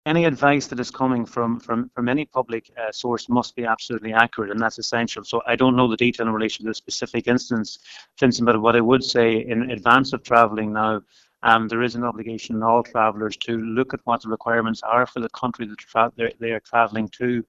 Agriculture Minister Charlie McConalogue says there needs to be confidence in the advice coming from the helpline: